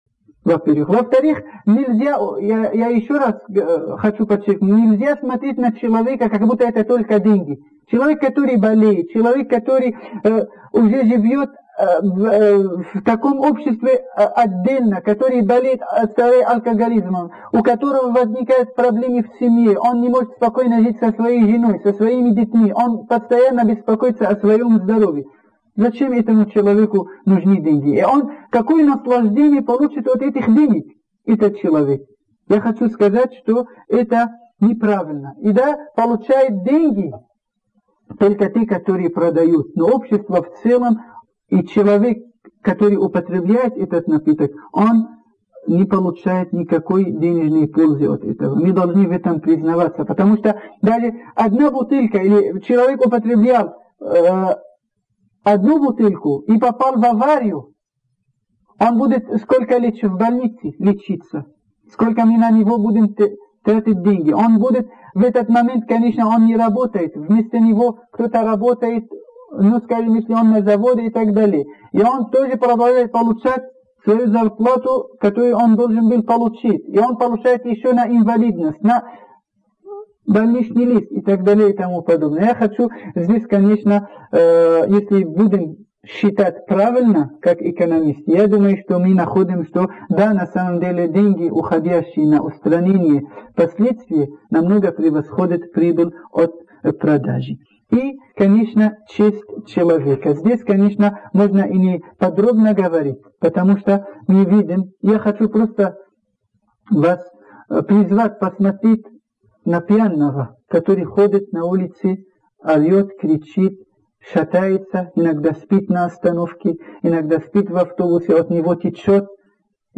Лекция об отношении Ислама к алкоголю.